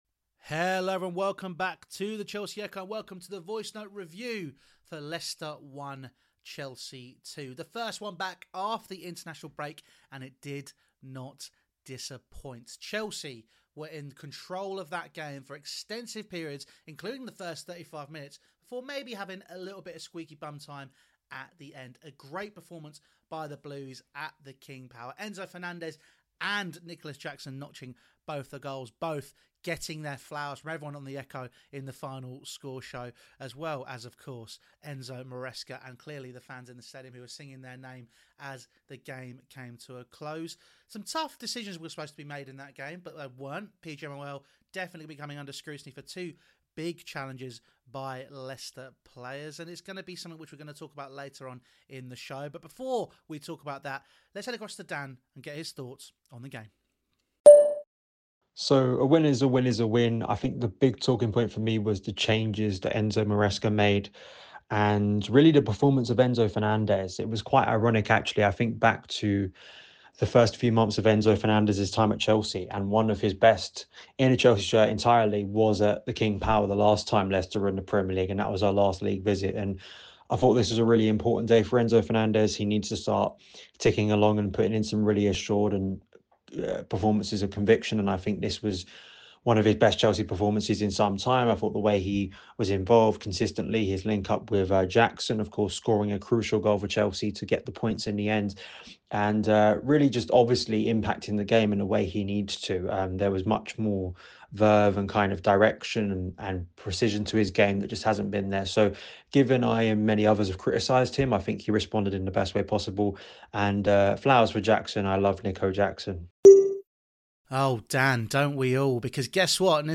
Enzo Fernandez: The Remontada | Leicester City 1-2 Chelsea | Voicenote Review